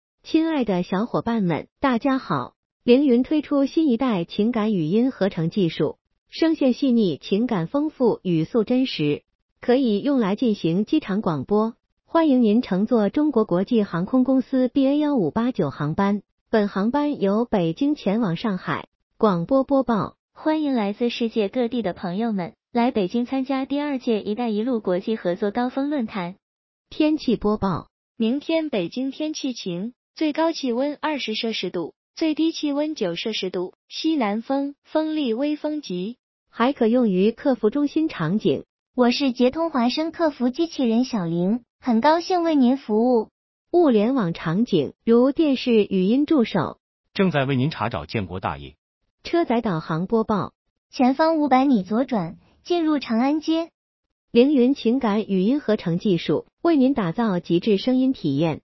业界动态:绘声绘色捷通华声灵云语音合成效果接近专业播音员
灵云最新情感语音合成录音地址：
生活中的这些语音播报，你一定很熟悉，但你可能想不到，这些流畅自然的声音，都是用机器合成出来的。
近期，捷通华声采用新一代算法，推出语音合成效果业界领先的TTS技术：基于高自然度韵律模型，实现与真人高相似度音色的模型训练，合成的声音声线细腻、情感丰富、语速真实。